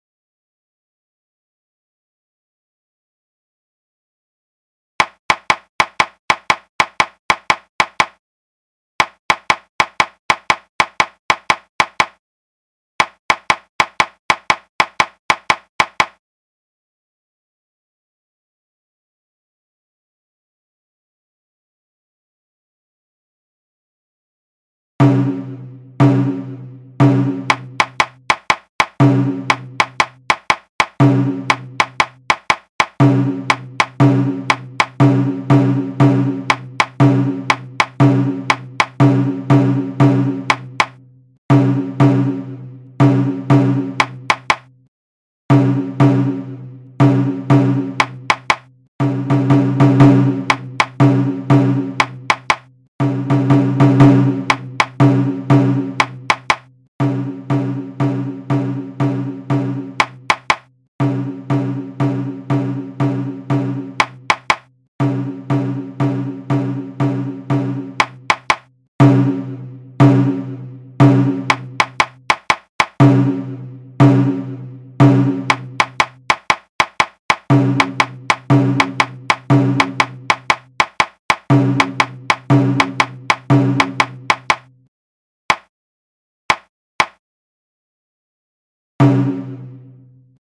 idea-matsuri-nagado+shime-95bpm-1000ms.mp3 (1.3M - updated 8 months, 3 weeks ago)